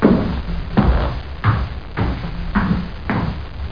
00088_Sound_WALKING2.FX